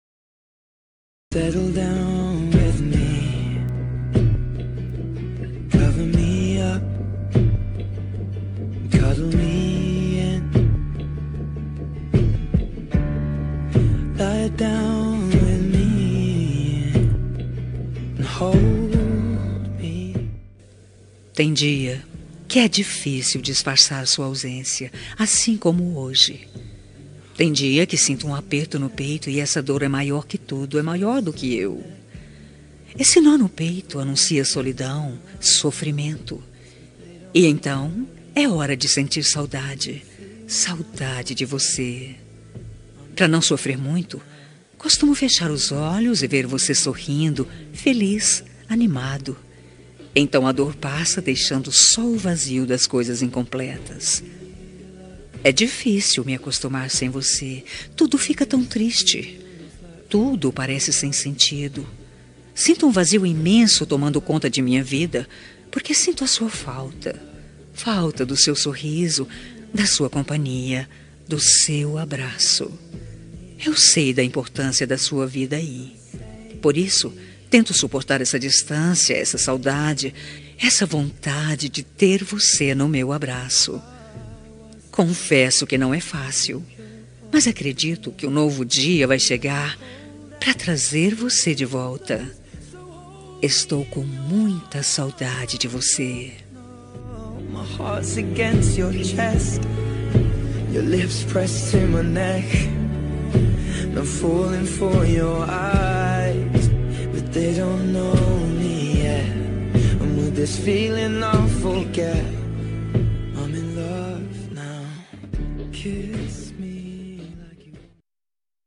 Telemensagem de Saudades – Voz Feminina – Cód: 4141